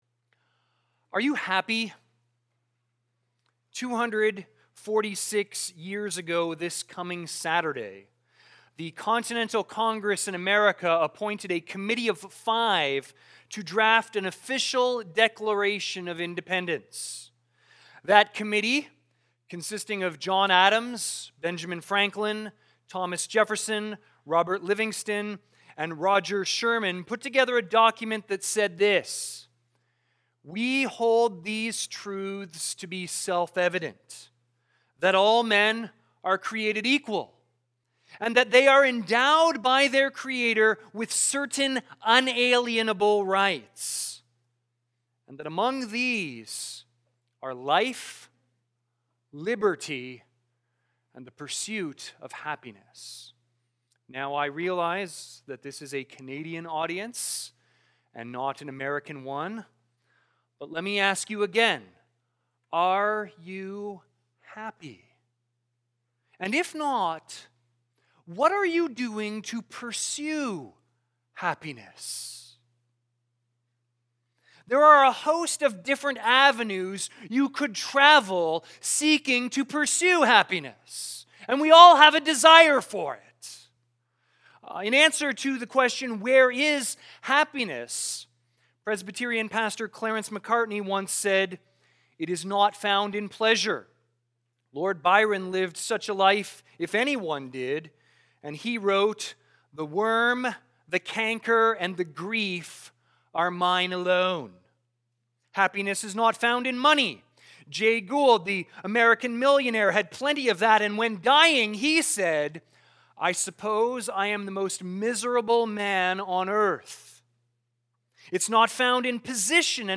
View the Sunday service. cbcwindsor · 2022-06-05 Sunday Service